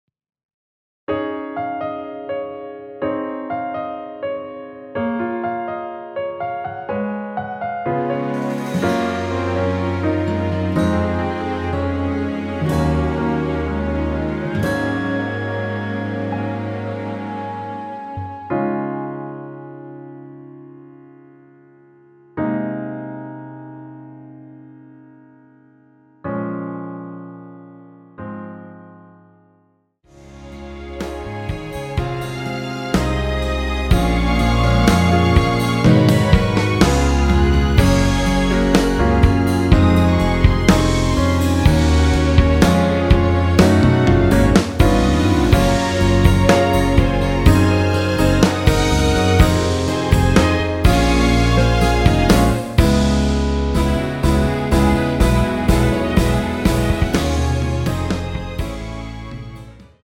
원곡 MR기준 4분 19초에서 3분21초로 짧게진행되는 MR입니다.
원키 1절후 후렴으로 진행 되는 MR입니다.(본문 가사 확인)
Db
앞부분30초, 뒷부분30초씩 편집해서 올려 드리고 있습니다.
중간에 음이 끈어지고 다시 나오는 이유는